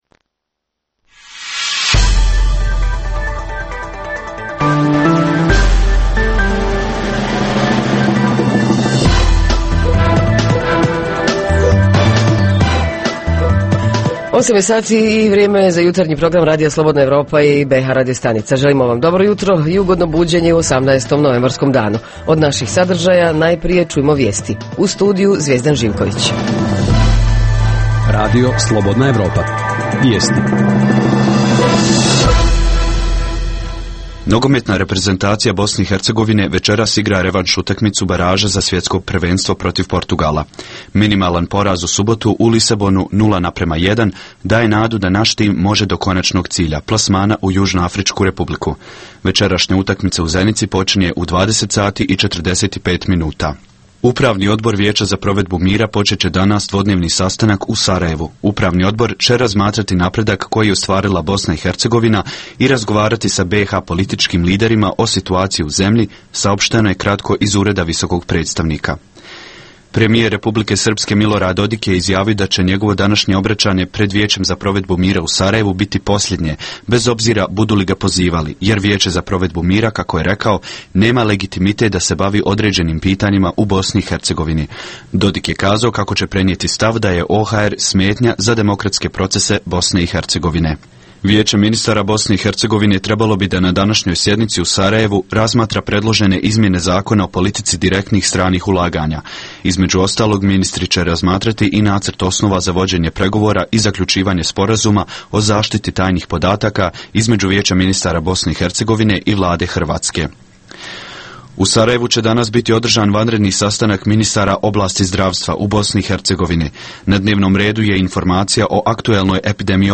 Razvoj voćarstva i podsticaj za rad - tema je jutarnjeg programa. Reporteri iz cijele BiH javljaju o najaktuelnijim događajima u njihovim sredinama.